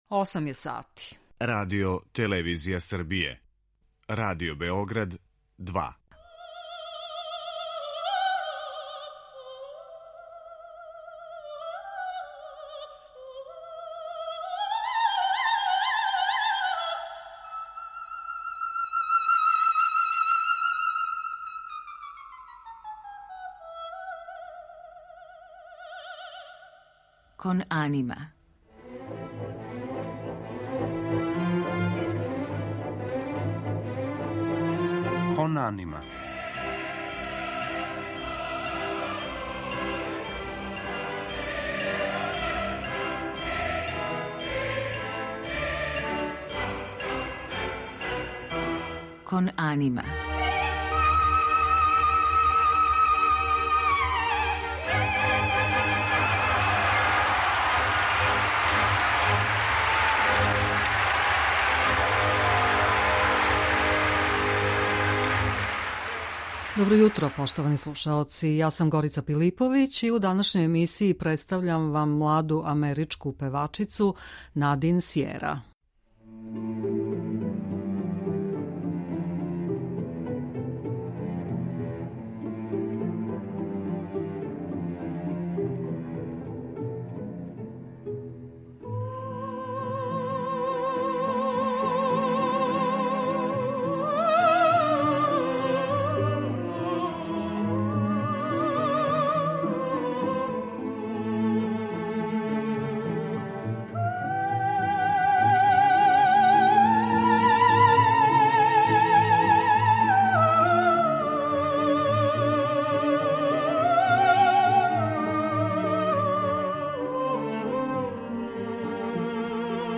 У емисији ћемо слушати одломке опера Доницетија, Вердија и Гуноа.